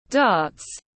Dart /dɑːt/